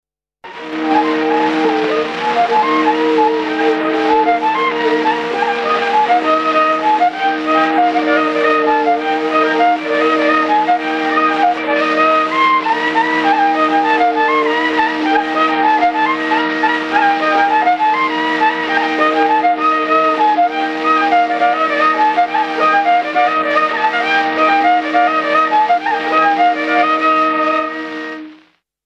I viiul
burdoonsaade
03 Labajalg.mp3